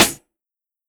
Snare Groovin 1.wav